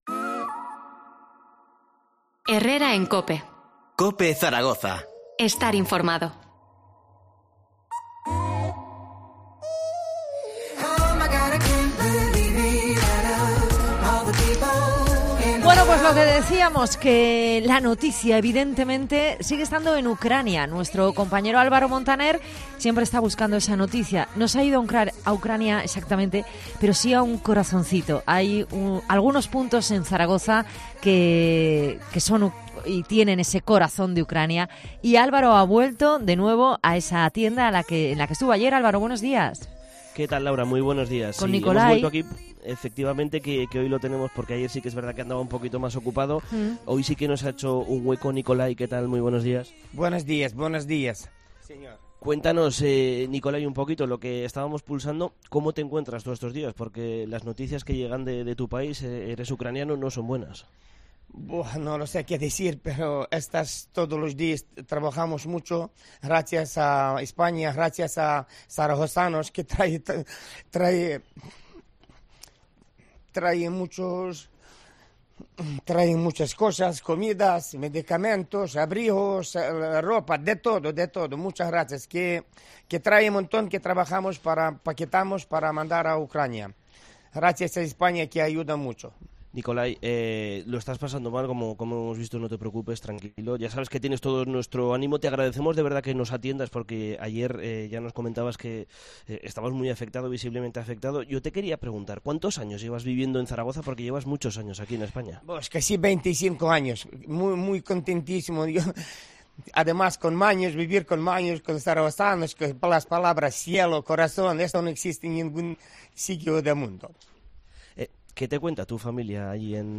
El miércoles desde COPE Zaragoza tuvimos la oportunidad de conocer de primera mano cómo se sentía.
Qué puedo decir más..." explica al tiempo que no puede reprimir sus lágrimas.